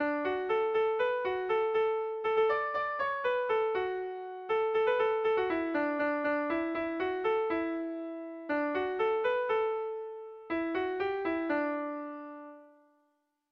Gabonetakoa
ABDEF